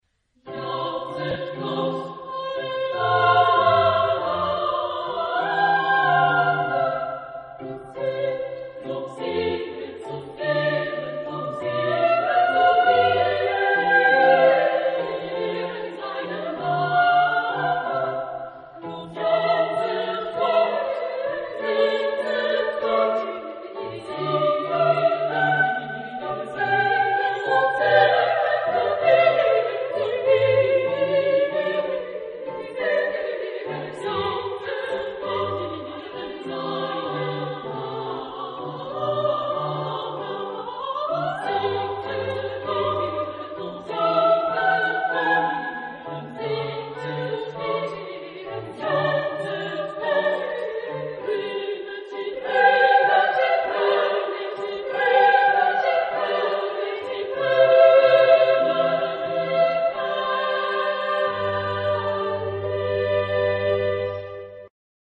Genre-Style-Forme : Sacré ; Motet ; Psaume ; Romantique
Caractère de la pièce : allegro moderato
Type de choeur : SSA  (3 voix égales de femmes )
Tonalité : do majeur